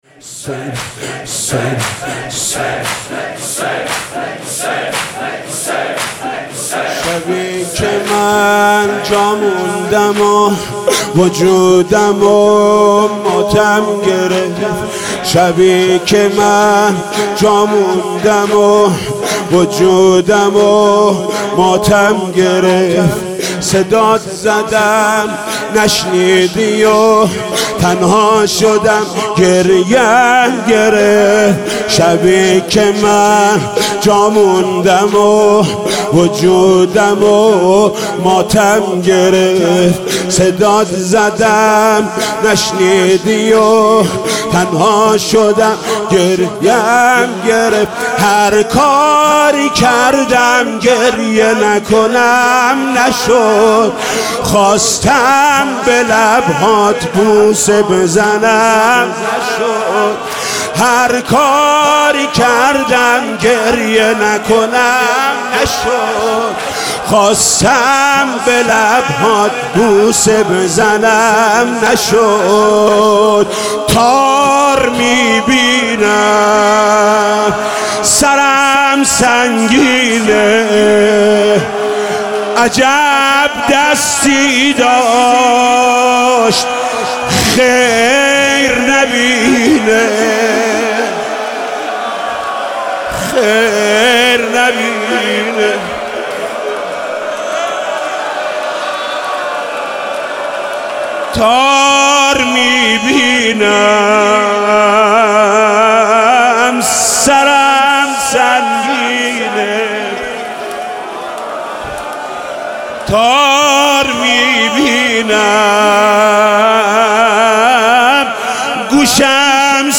شب سوم محرم95/هیئت رایه العباس